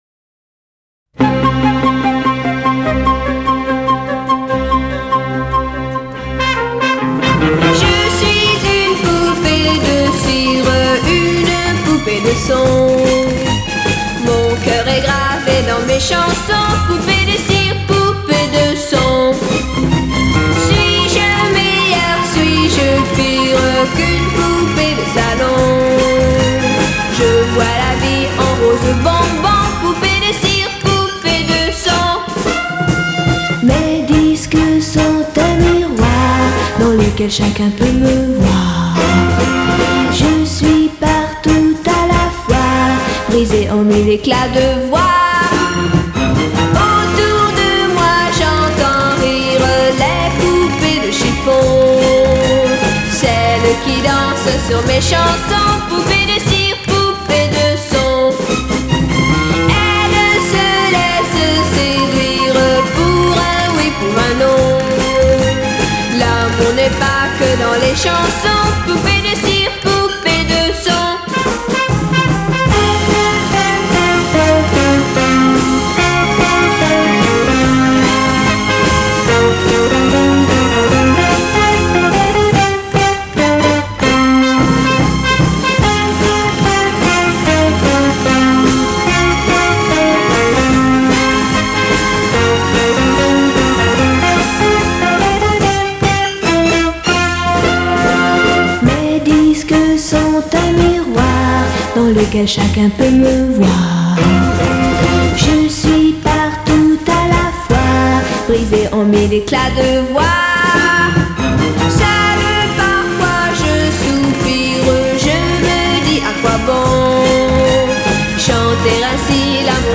那欢快流畅的旋律，那天真无邪的声音会把你带到以前的那个青葱年代。